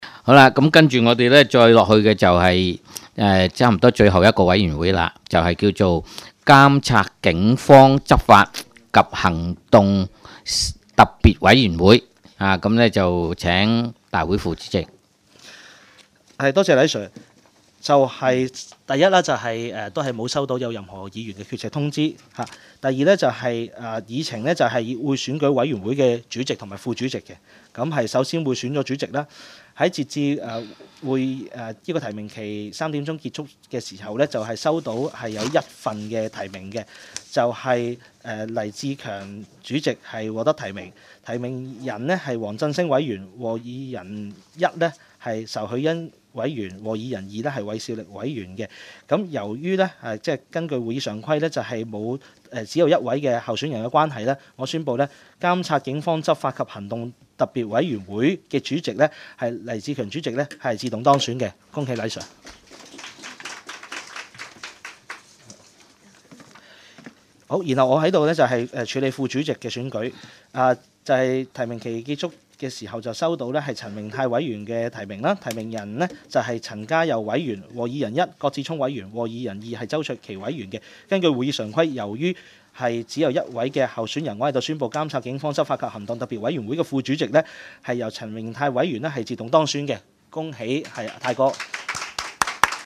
委员会会议的录音记录
地点: 香港西湾河太安街 29 号 东区法院大楼 11 楼东区区议会会议室